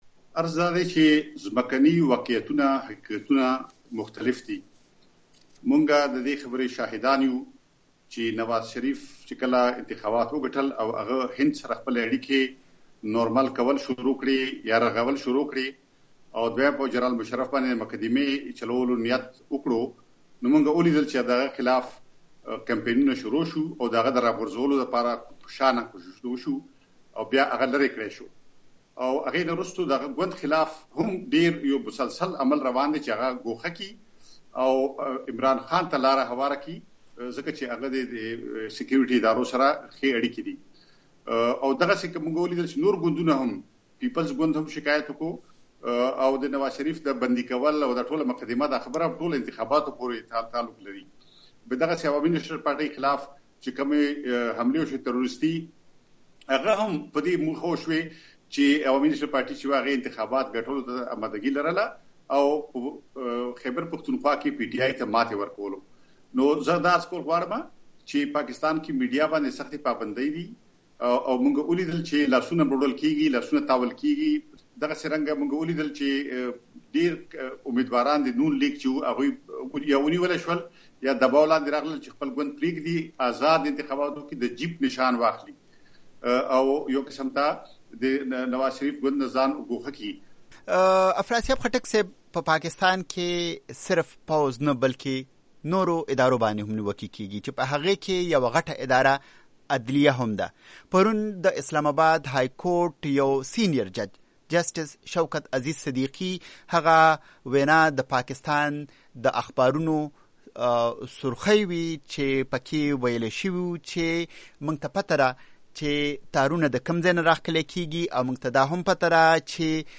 افراسیاب خټک مرکه